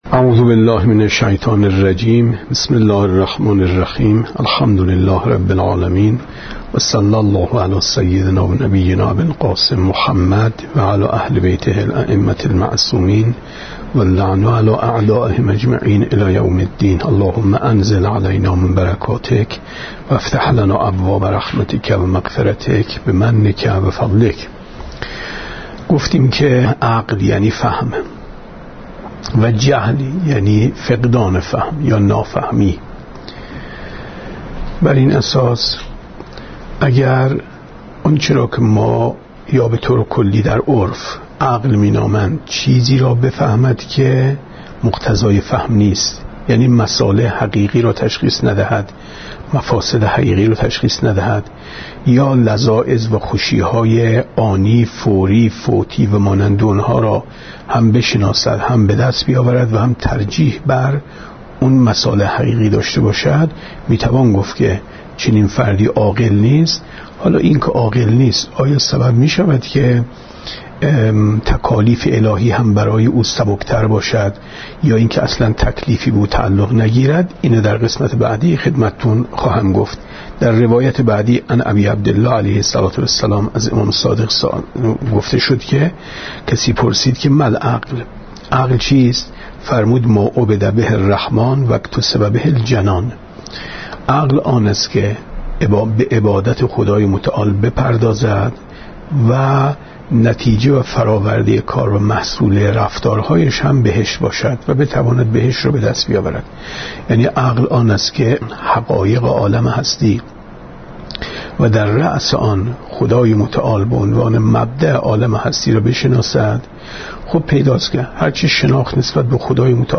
گفتارهای ماه مبارک رمضان 1436 ـ جلسه سوم ـ 4/ 4/ 94 ـ شب نهم ماه رمضان